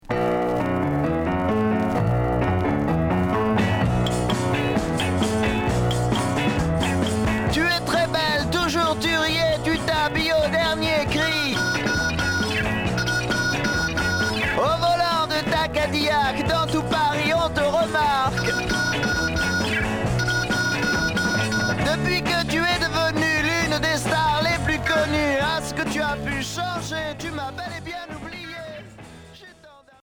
Garage 60's Deuxième EP retour à l'accueil